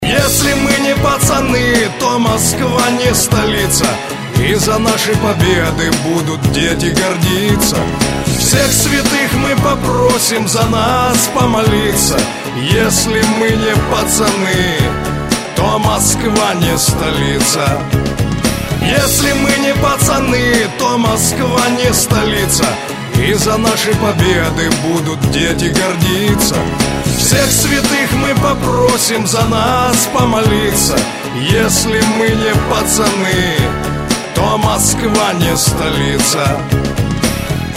• Качество: 128, Stereo
блатные
дворовые